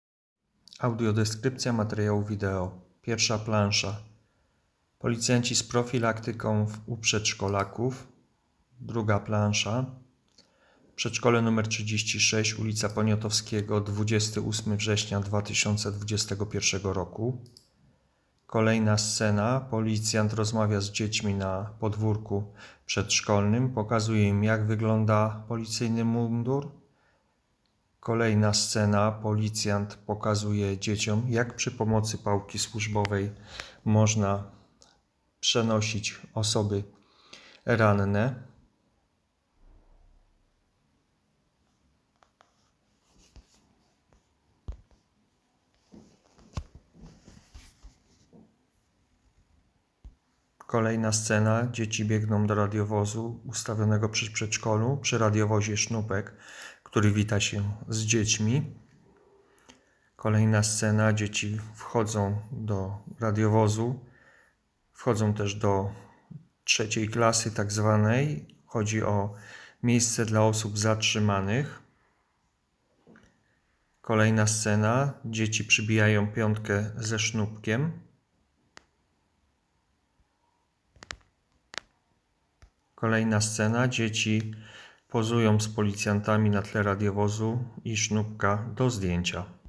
Opis nagrania: Audiodeskrypcja materiału video